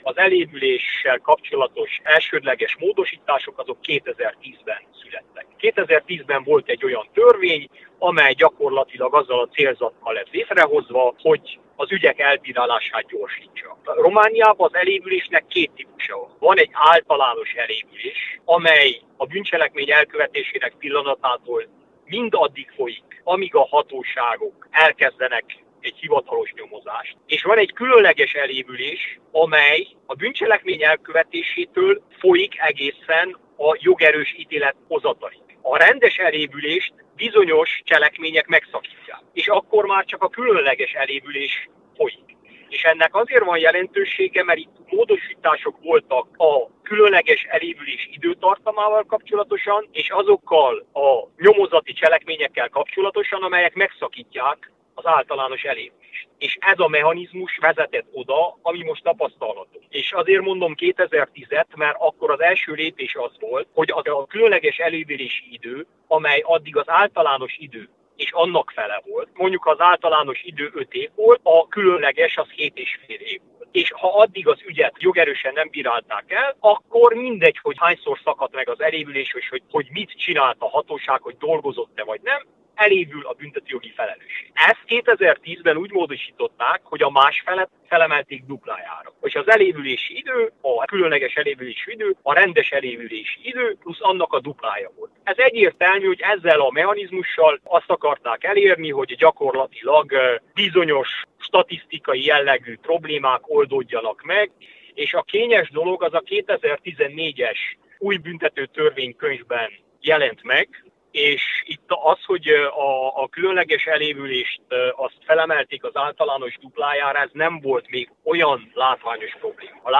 A Marosvásárhelyi Rádió által megkérdezett büntetőjogi szakértő szerint nem ez a jellemző a román igazságszolgáltatásban, ugyanakkor az is tény, hogy a kisebb ügyekben is előfordulnak elévülések, amelyek elégedetlenséget váltanak ki.